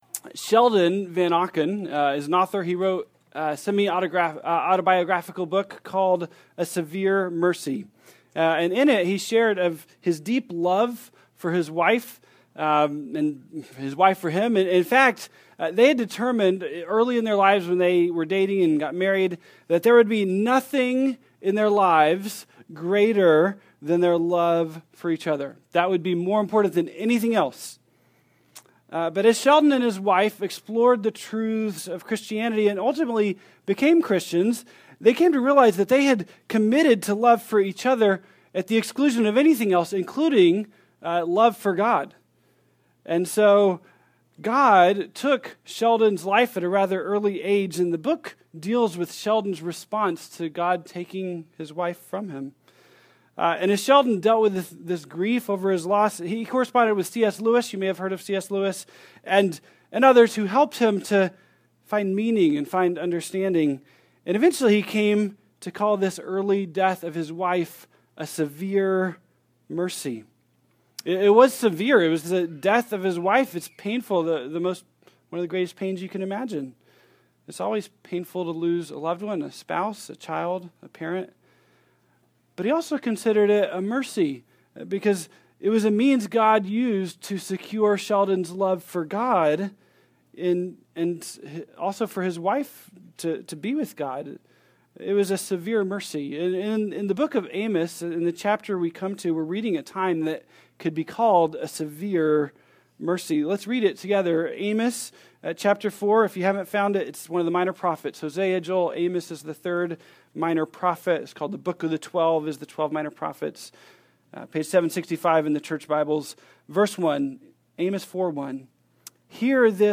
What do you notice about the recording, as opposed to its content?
2016 ( Sunday AM ) Bible Text